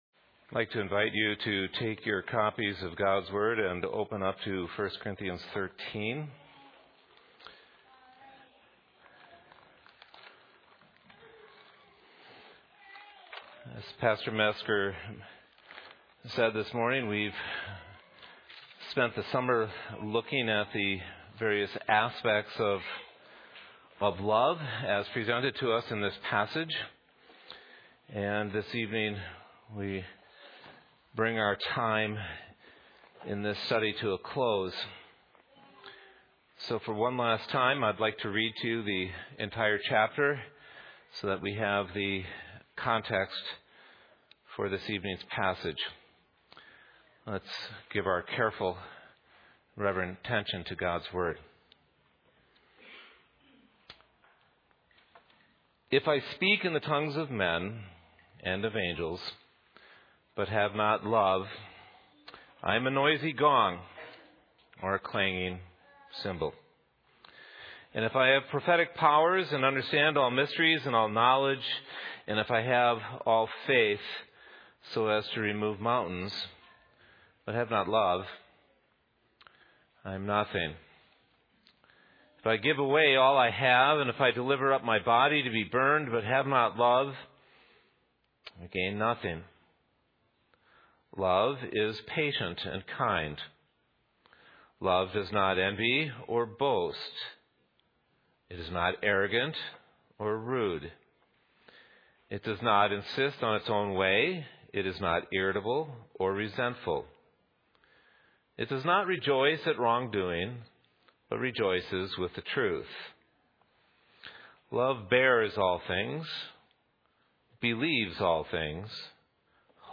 Passage: Matthew 25:1-13 Service Type: Evening